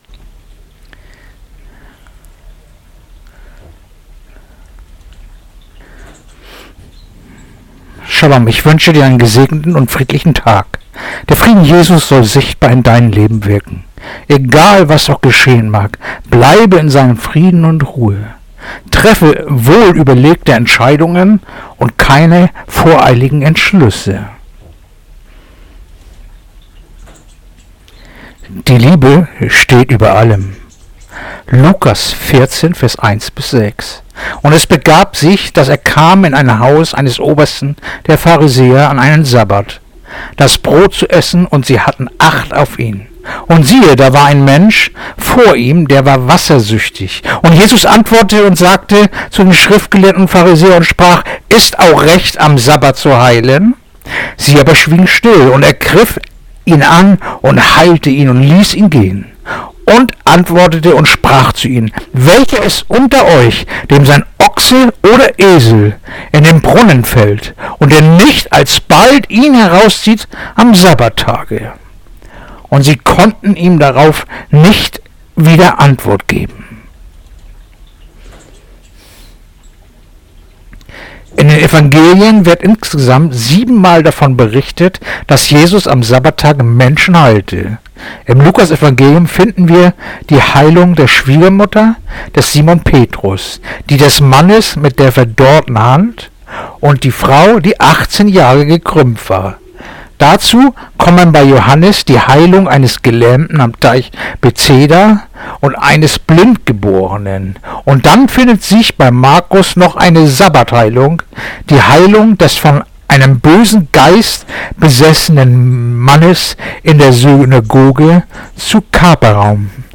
Andacht-vom-07.-Juli-Lukas-14-34-35